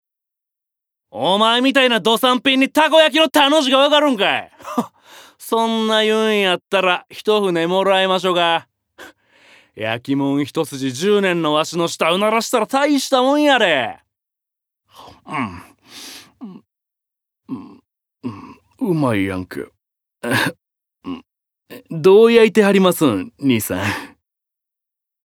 ボイスサンプル
セリフ７